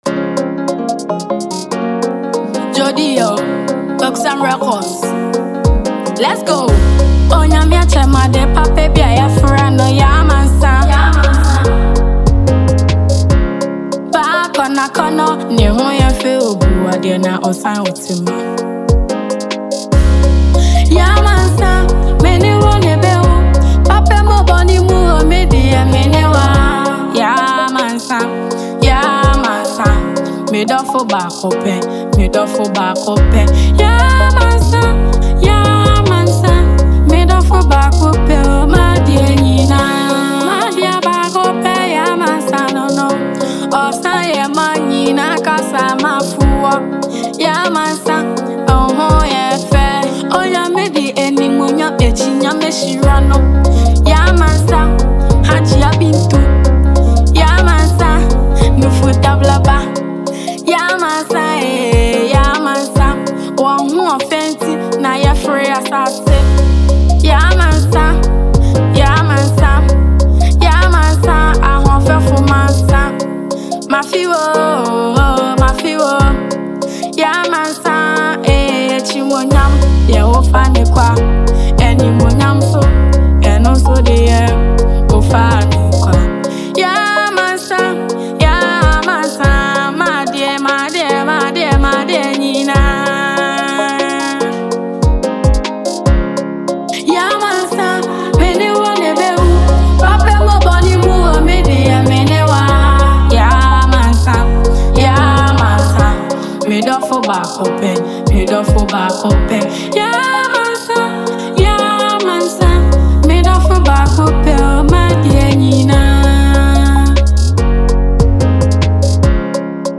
love song
soulful vocals and emotional delivery
With its catchy melody and infectious rhythm,
highlife song